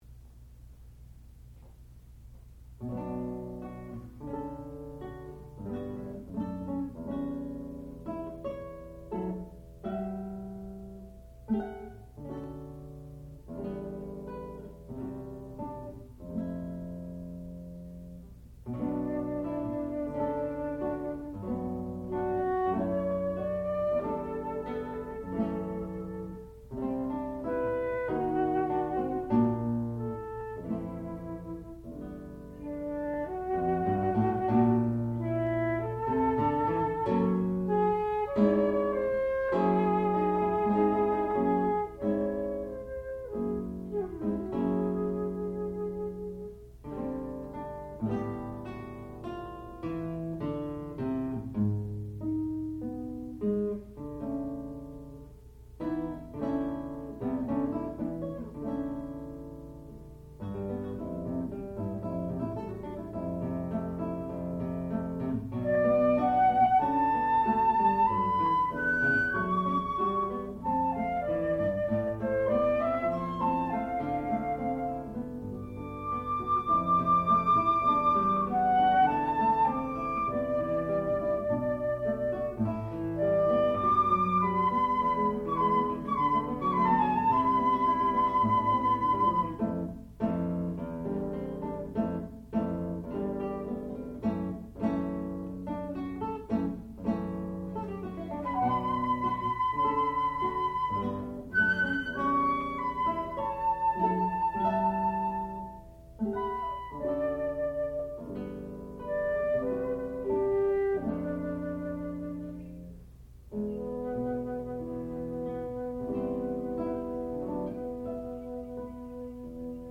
Four Excursions for Guitar and Flute (1971)
classical music
Advanced Recital